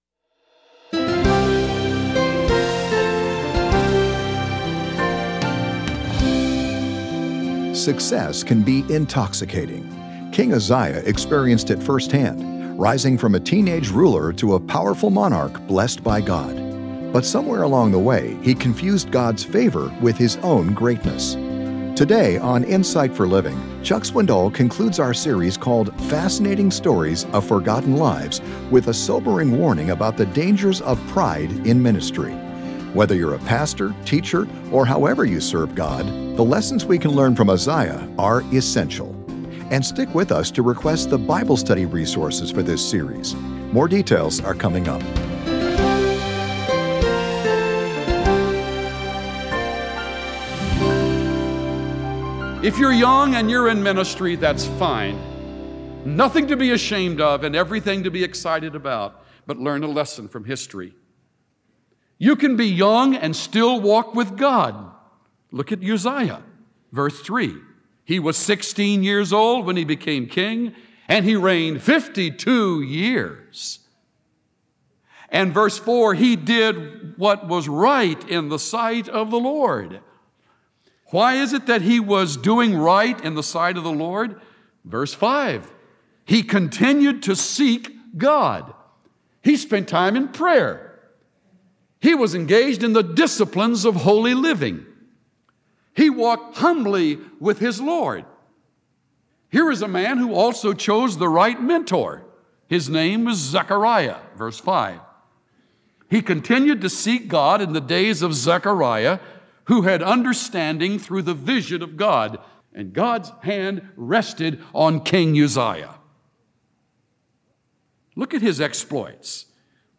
The rise and fall of King Uzziah, recorded in 2 Chronicles 26, is a pertinent example of this tragedy. Tune in to hear Pastor Chuck Swindoll teach on pride, leadership, and God’s glory. Learn the telltale signs of a ministry or a leader hoarding glory for themselves instead of giving it back to God.